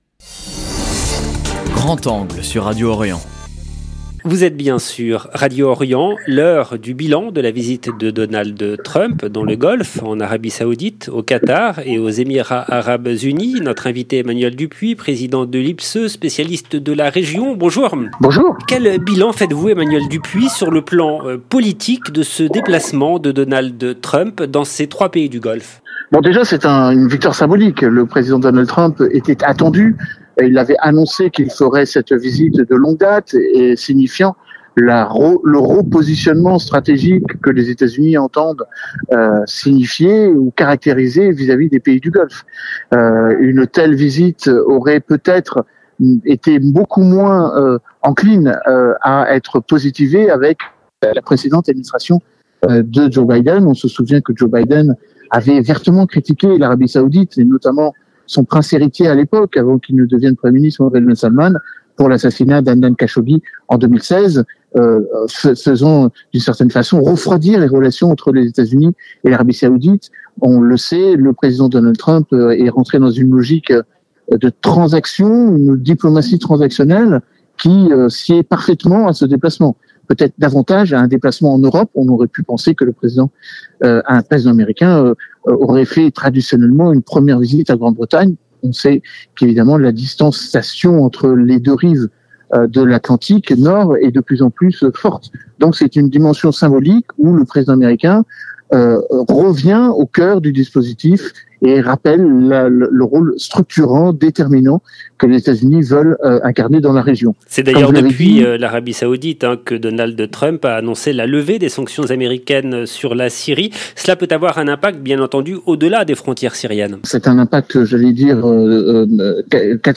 Au cours de cet entretien, il reviendra aussi sur l’annonce de la levée des sanctions américaines sur la Syrie.